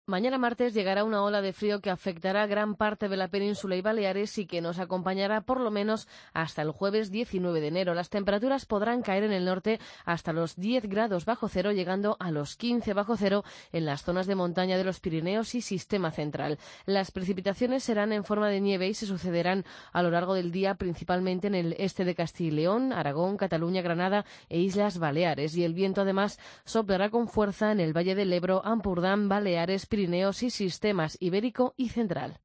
La previsión del tiempo para este martes